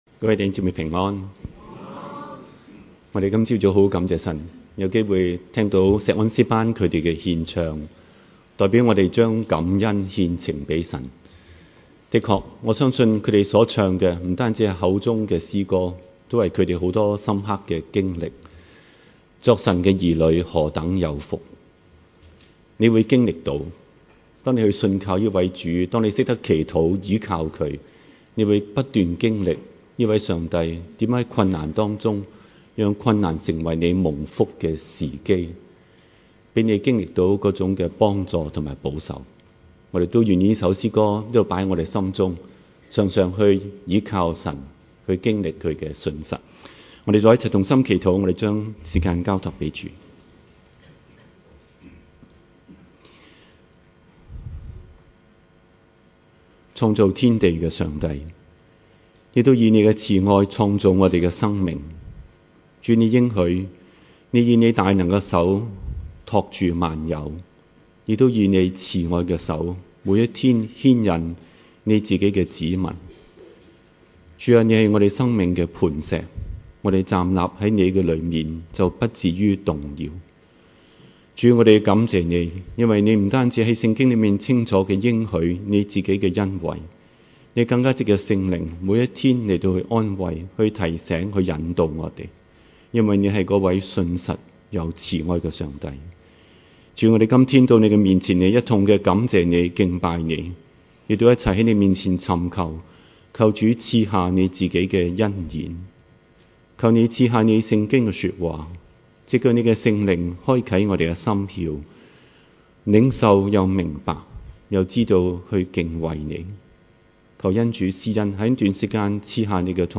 場所：週六崇拜